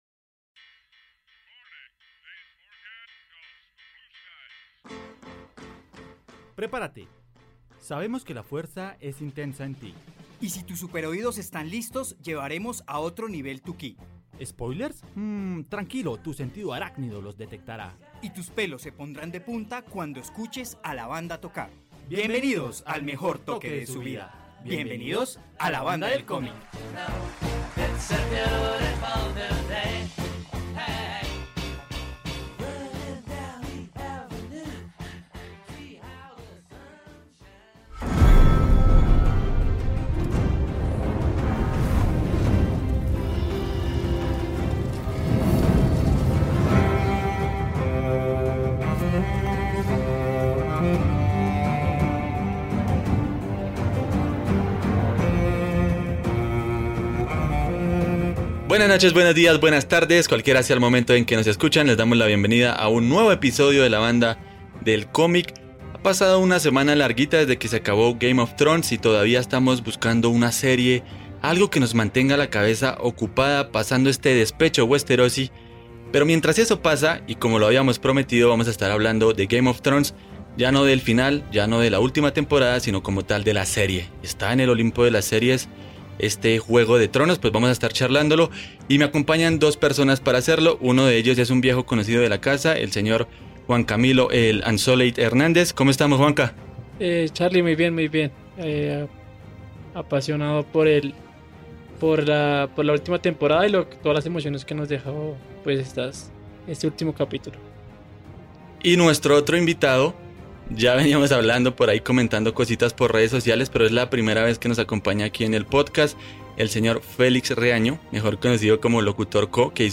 al ritmo de canciones que gracias a la serie no olvidaremos nunca.